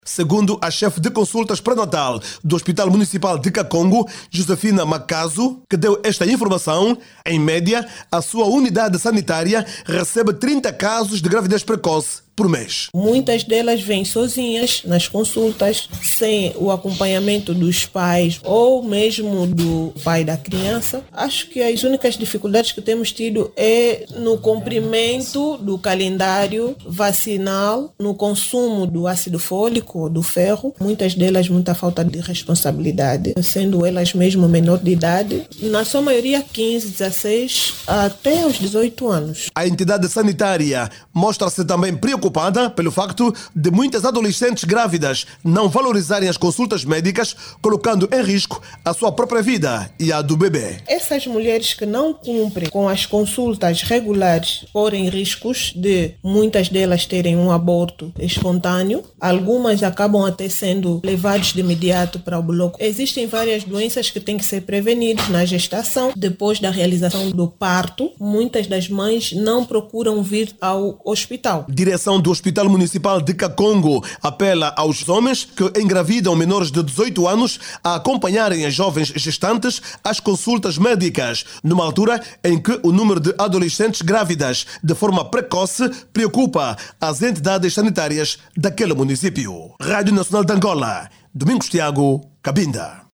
O Hospital Municipal de Cacongo,  em Cabinda, regista em média por mês trinta casos de gravidez precoce situação que preocupa as autoridades sanitárias locais. Outra preocupação é a baixa procura pelas consultas pré-natais, que tem comprometido o acompanhamento adequado da gestão e da saúde materno infantil. Clique no áudio abaixo e ouça a reportagem